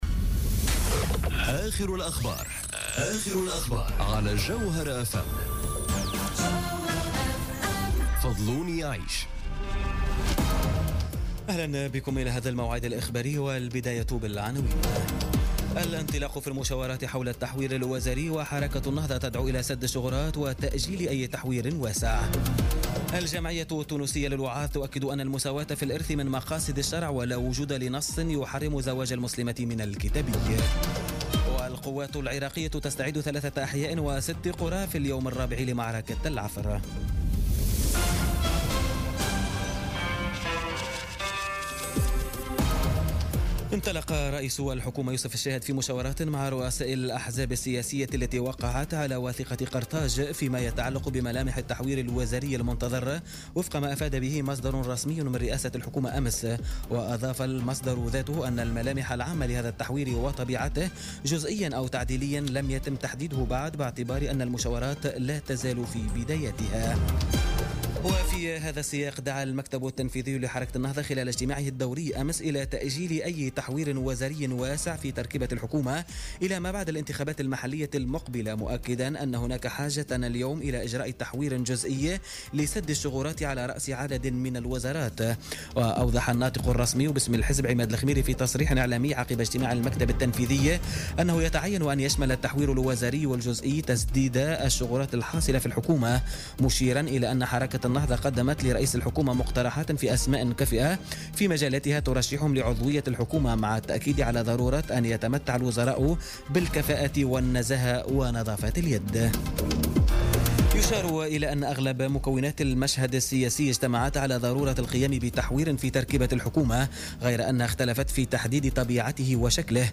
نشرة أخبار منتصف الليل ليوم الخميس 24 أوت 2017